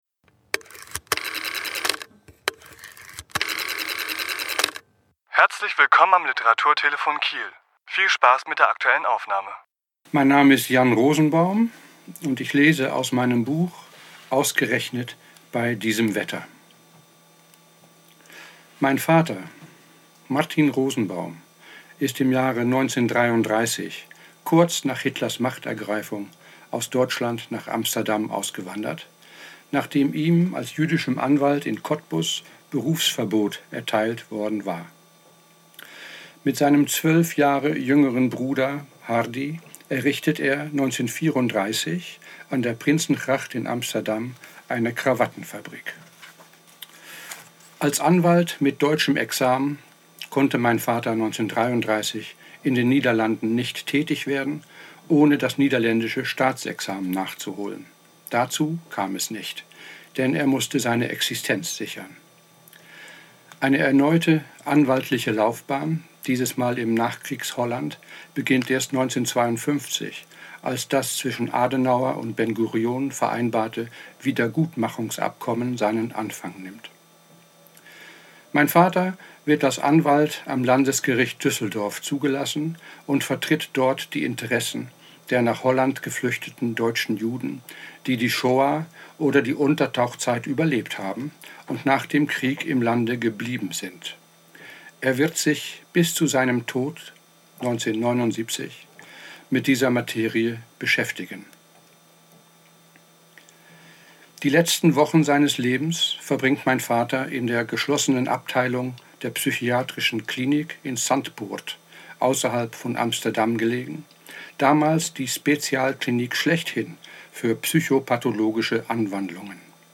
Autor*innen lesen aus ihren Werken
Die Aufnahme entstand am 30.1.2016 im Rahmen einer Lesung im Flandernbunker, veranstaltet in Kooperation des Literaturhauses Schleswig-Holstein und des Mahnmal Kilian e.V.